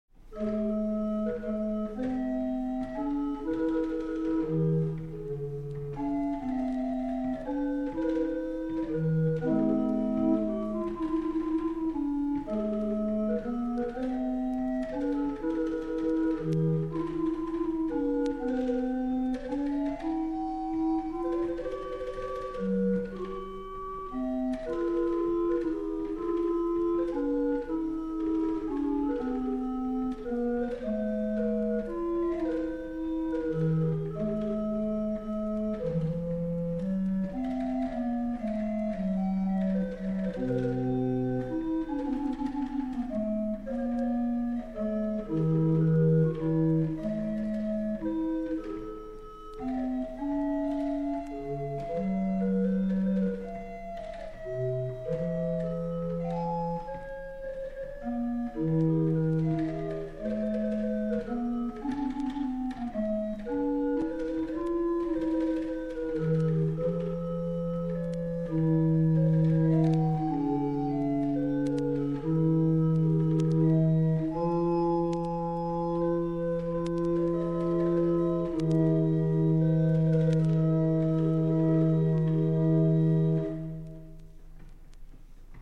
Also, I've been looking at a performance of Haendel, as preserved in a historic barrel organ: